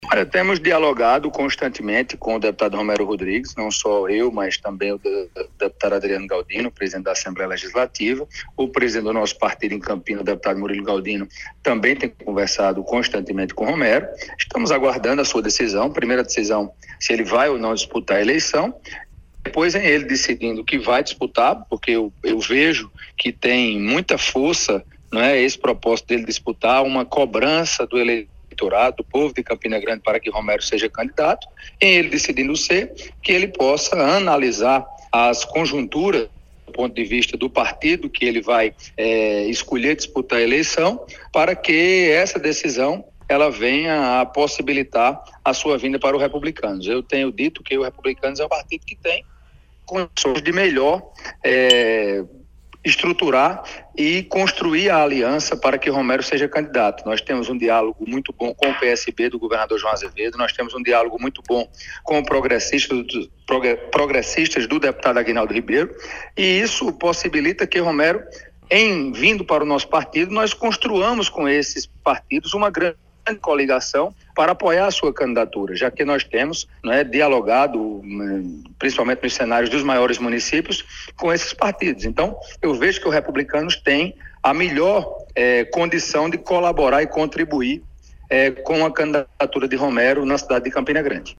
Os comentários de Motta foram registrados pelo programa Correio Debate, da 98 FM, de João Pessoa, nesta segunda-feira (21/08).